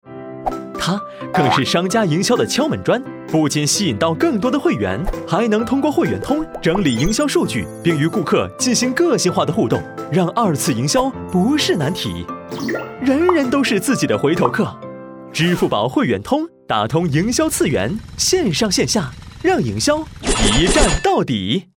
男英4号-英语配音-自然放松-飞碟说